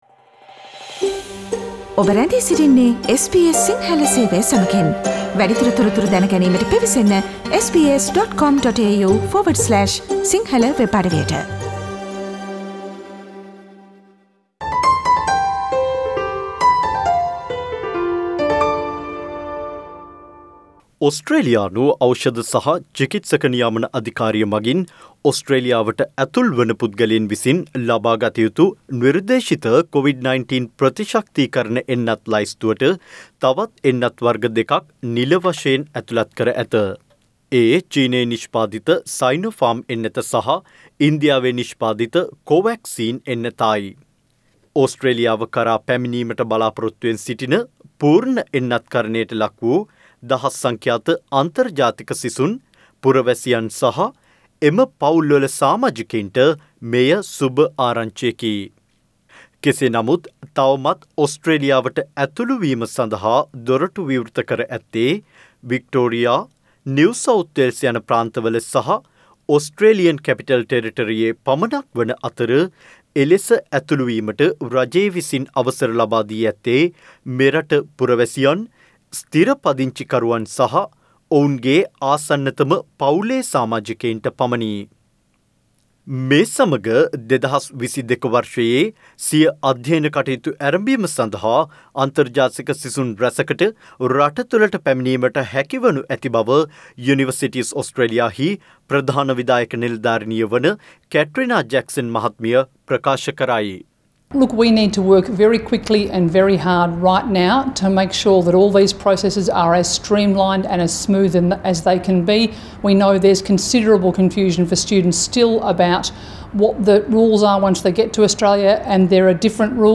SBS Sinhala Radio current Affair Feature on Friday 5 November 2021.